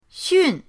xun4.mp3